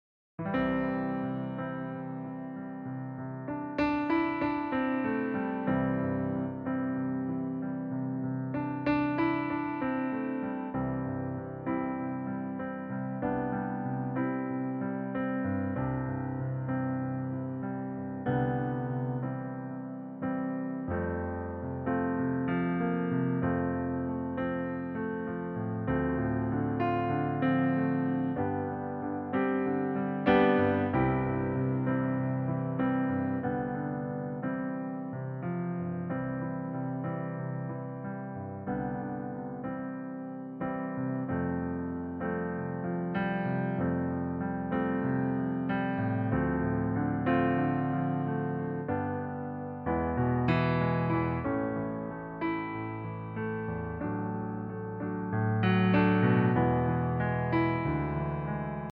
Demo in C-Dur